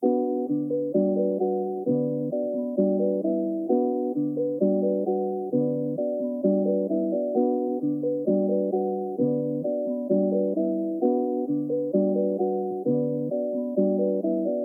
Tag: 131 bpm Hip Hop Loops Bells Loops 2.47 MB wav Key : A FL Studio